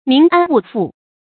民安物阜 mín ān wù fù
民安物阜发音